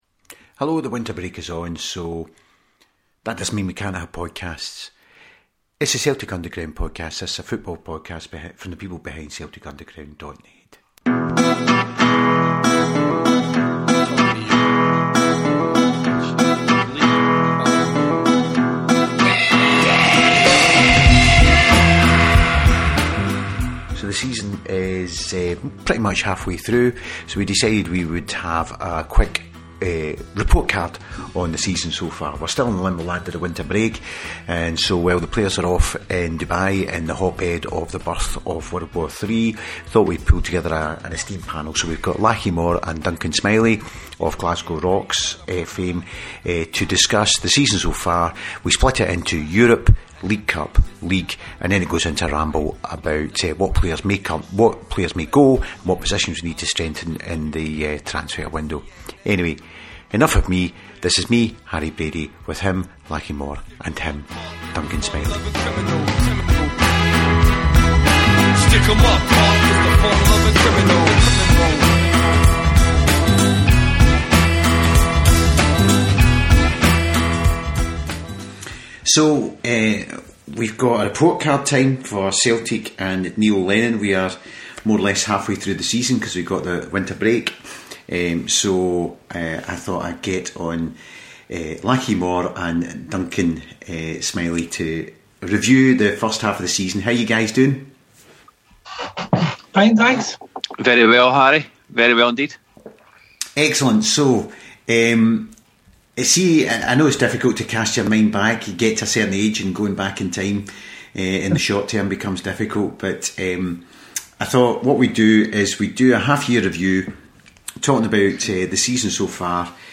We’re still in the limbo-land of the winter break and whilst the players are training in the hotbed of the birth of World Ward III we here at CU Towers thought there needed to be a half year report card for the hoops. We therefore decided we needed a panel of esteemed football experts to review the season so far and in the best Juke Box Jury fashion ascribe whether it had been a hot or a miss.